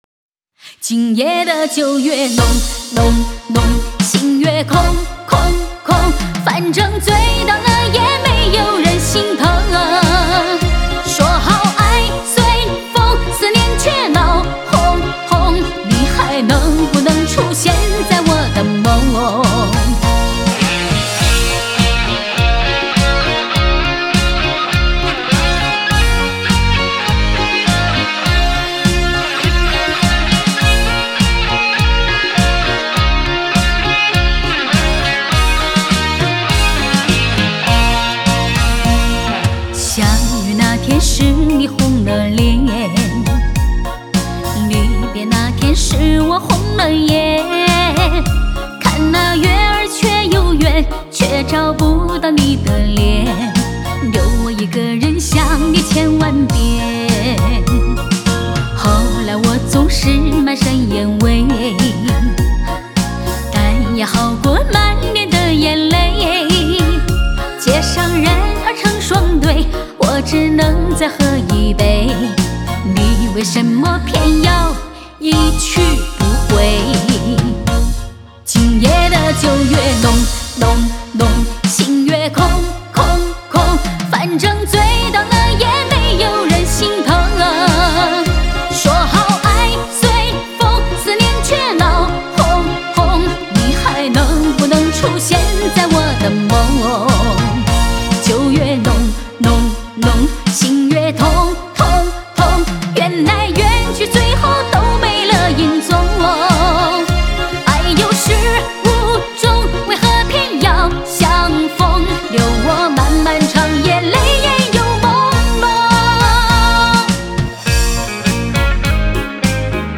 拍号为4/4拍，歌曲速度为每分钟102拍